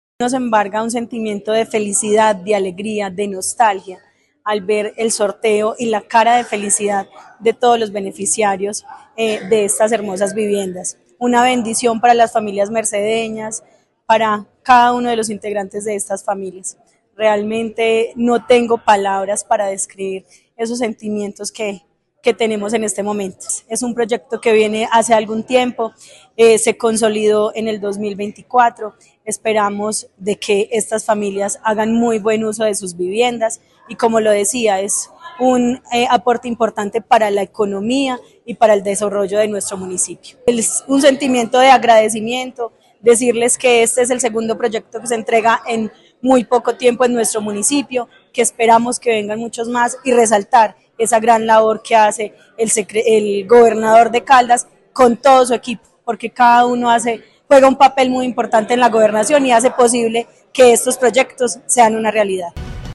Yenny Henao, alcaldesa de La Merced.
Yenny-Henao-–-Alcaldesa-La-Merced-Sorteo-Vivienda-La-Merced.mp3